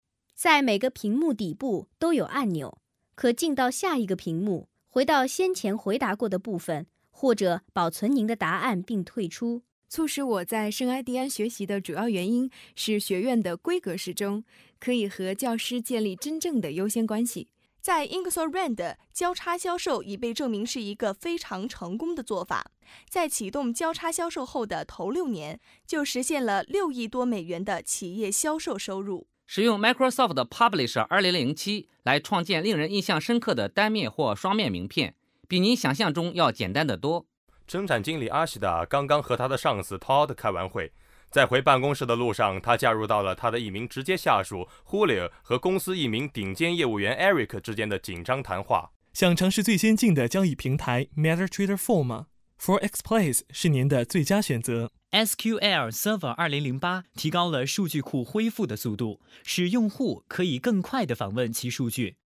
Sprecher chinesisch, Chinese, Cantonese, Mandarin, Japanese, Korean, Voice over
Sprechprobe: Werbung (Muttersprache):